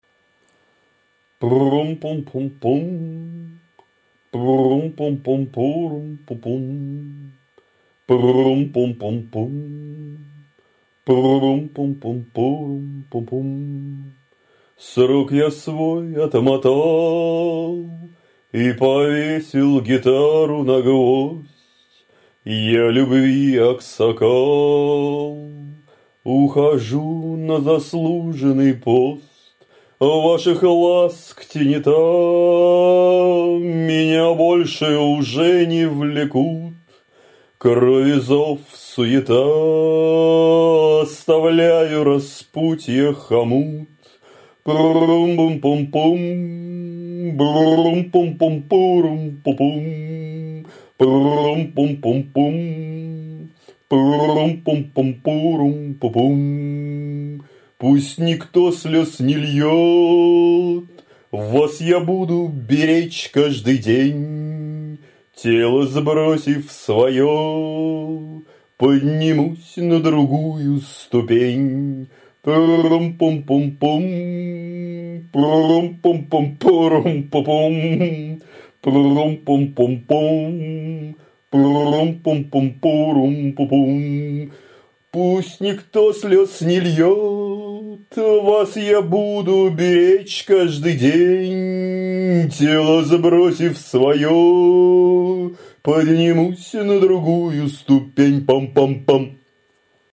Стихи-с:
Эпитаф (это ужжже моё, и завывания мои :crazyfun:)